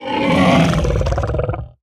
Minecraft Version Minecraft Version 1.21.4 Latest Release | Latest Snapshot 1.21.4 / assets / minecraft / sounds / mob / hoglin / converted2.ogg Compare With Compare With Latest Release | Latest Snapshot